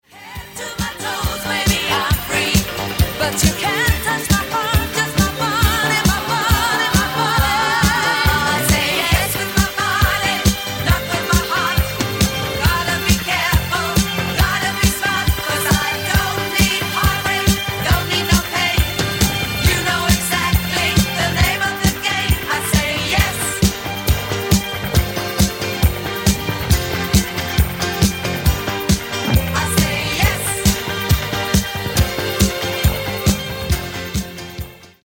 Genere: Disco